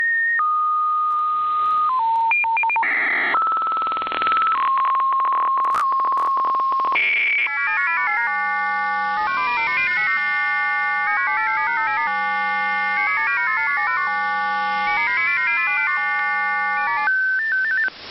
Audio of the russian F07 number station.